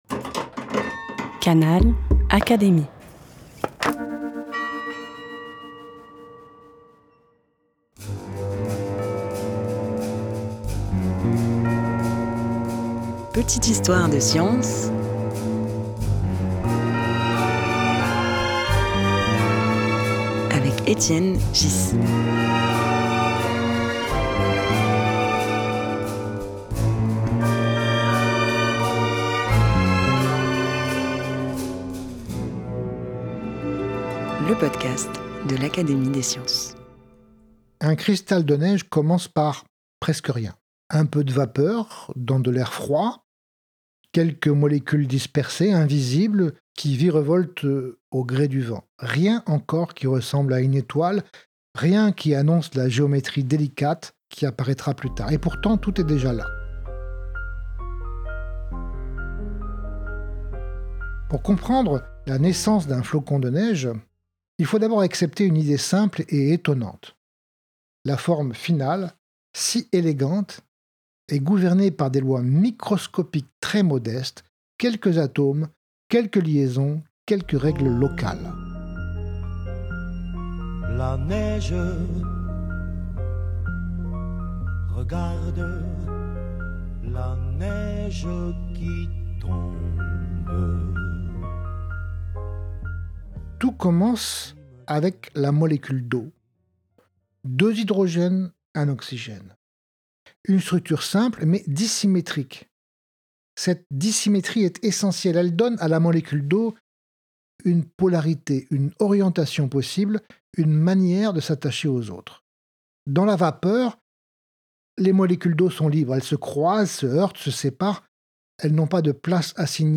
Petites histoires de science est un podcast animé par Étienne Ghys, Secrétaire perpétuel de l'Académie des sciences.